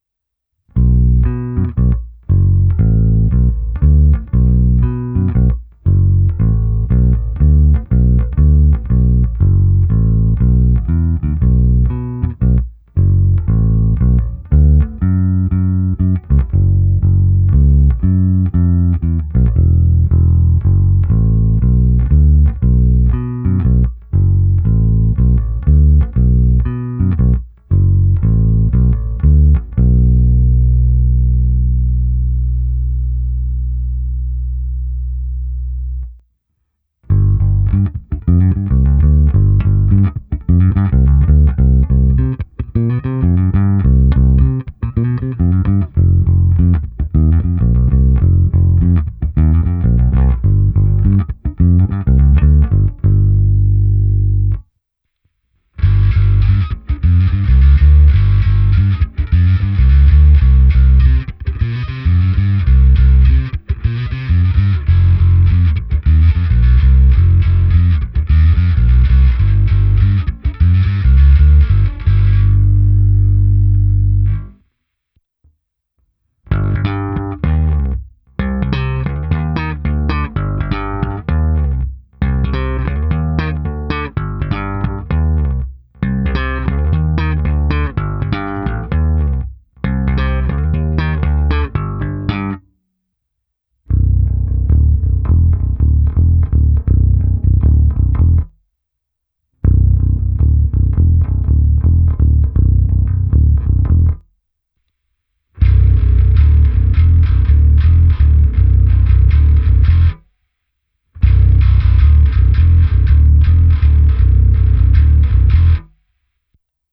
Nahrávka v pasivu na samotný P snímač skrz preamp Darkglass Harmonic Booster, kompresor TC Electronic SpectraComp a preamp se simulací aparátu a se zkreslením Darkglass Microtubes X Ultra. V nahrávce jsem použil i zkreslení a slapovou techniku, na konci je ještě ukázka struny H bez a se zkreslením. Opět roundwound a flatwound struny.
Ukázka se simulací aparátu FW